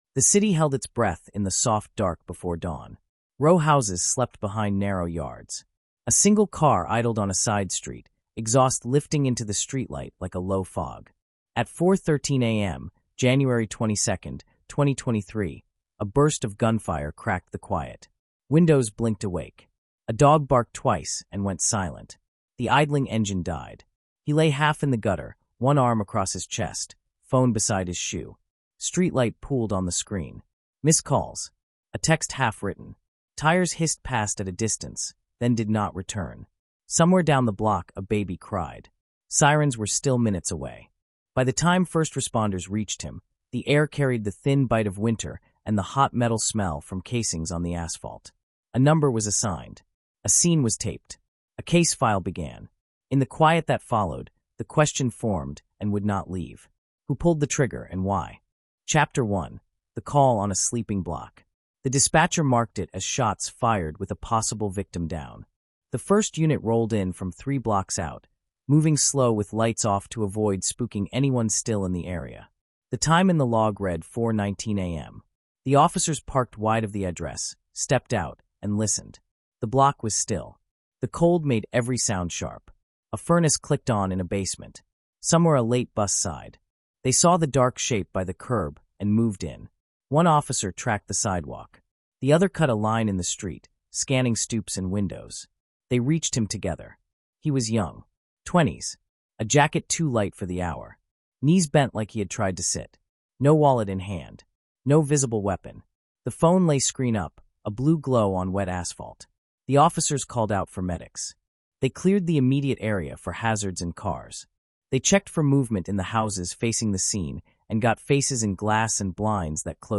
Told like a cinematic true-crime drama